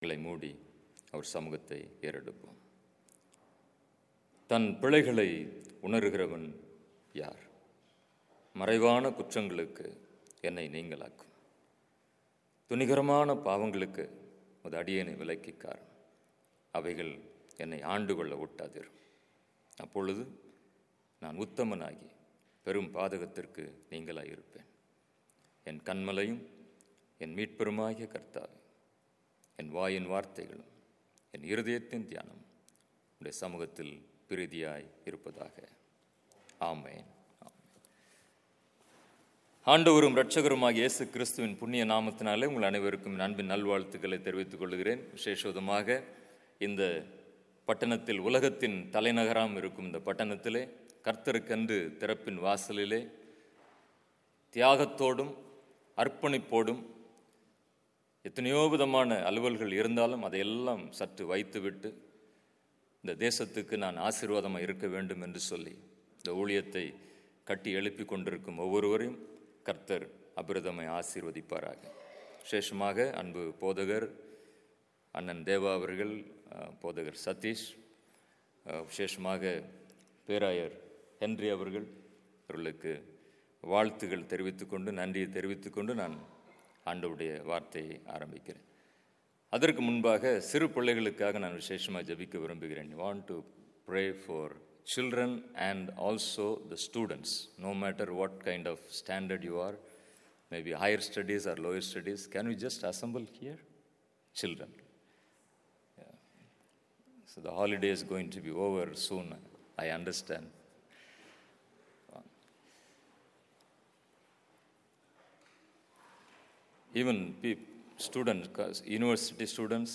SERMON / Calling and Blessings